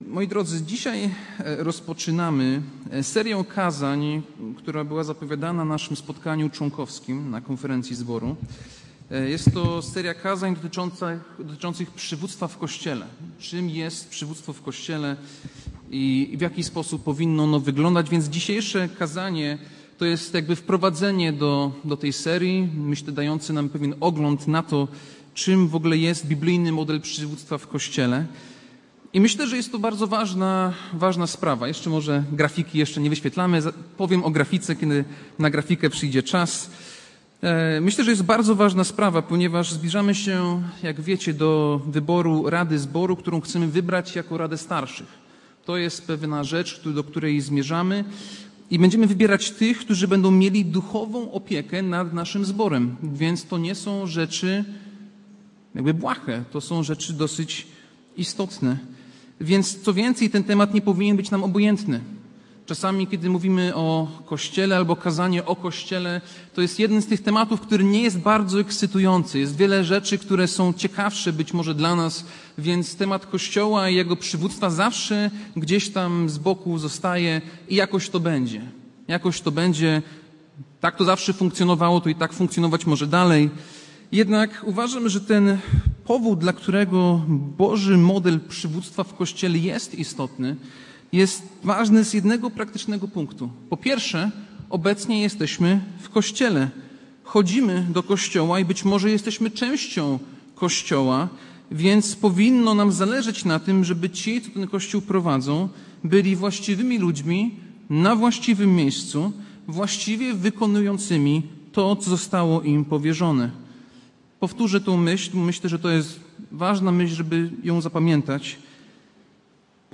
Kazanie